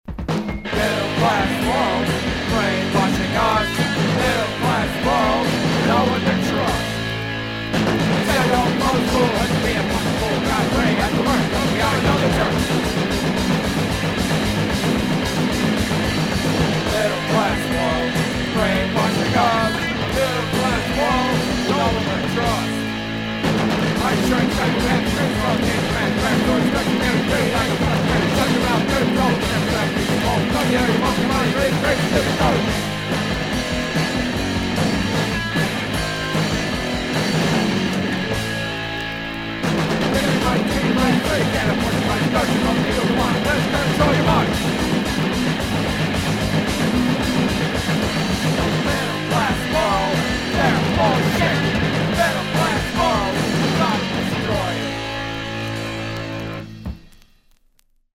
Average at best hardcore with a rather annoying singer.